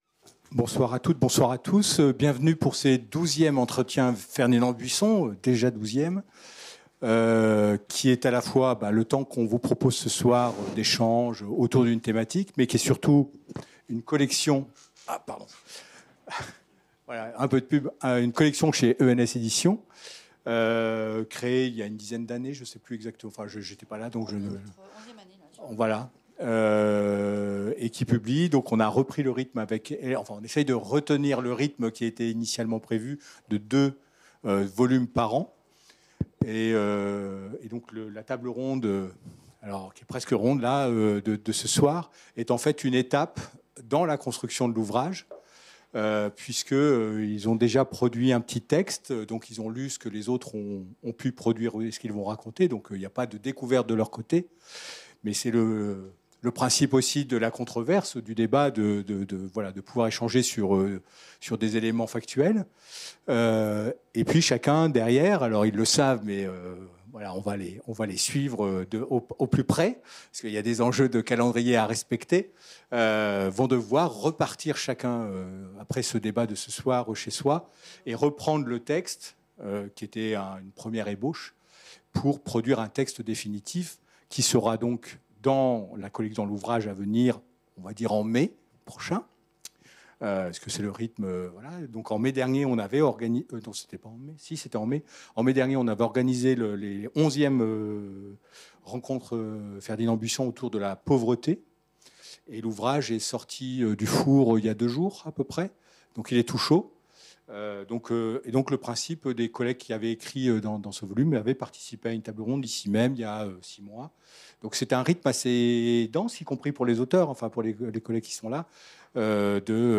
La table ronde rassemblait 6 participants :